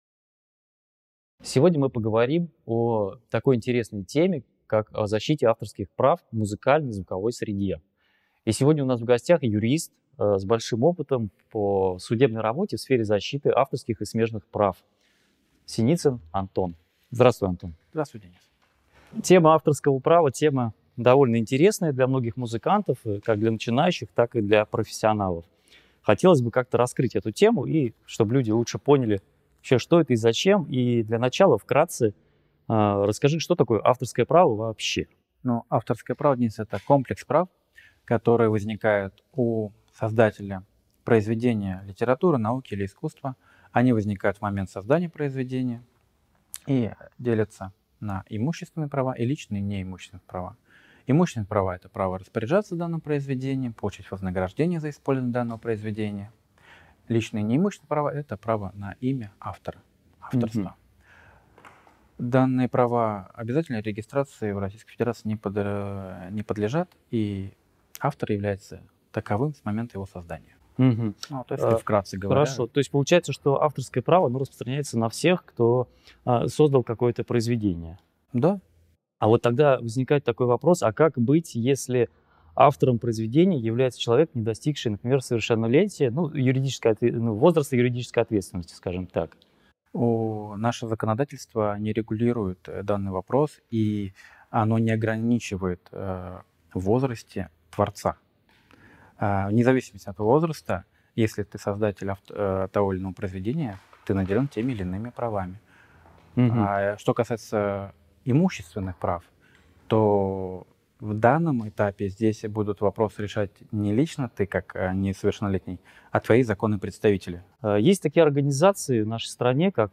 Готовлю большое интервью с юристом (собираю вопросы)